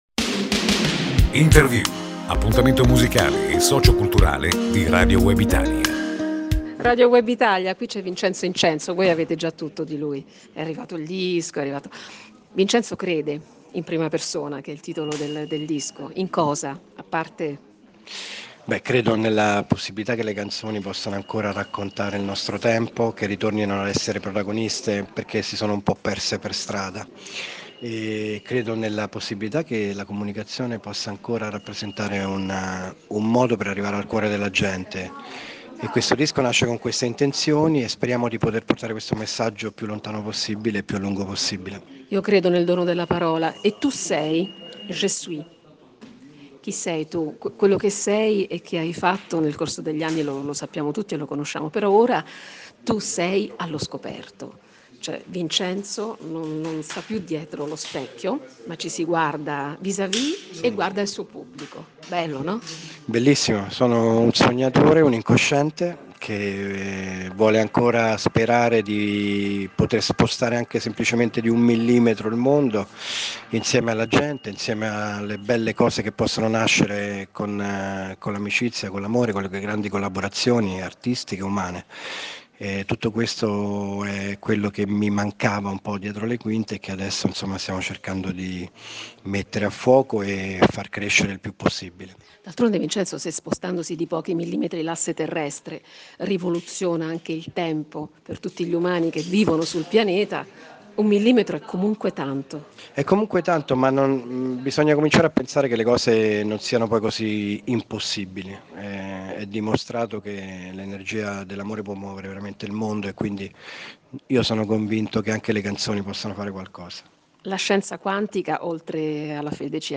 Intervista a Vincenzo Incenzo